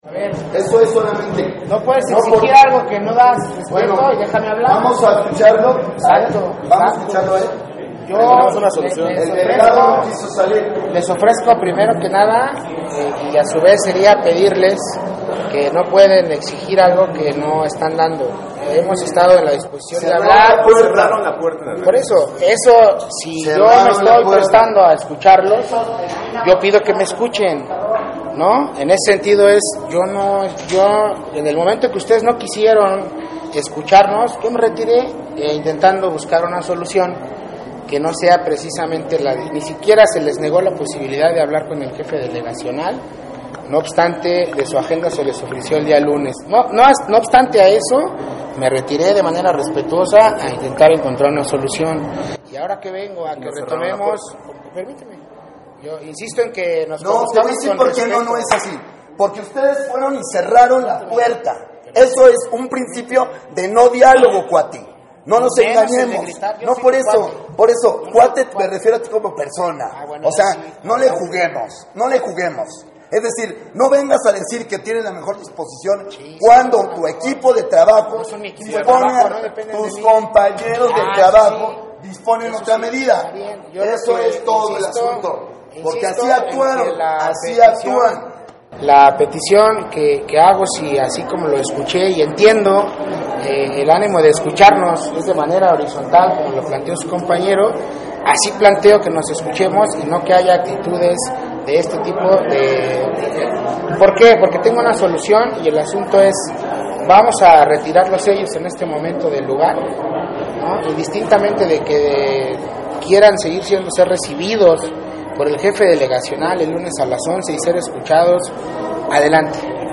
Ante esta  agresión, integrantes de La Karakola y compañerxs solidarixs, llevaron a cabo un acto-protesta ante la Jefatura de la Delegación Cuauhtémoc con el fin de exigir el retiro de sellos de clausura del espacio autónomo liberado.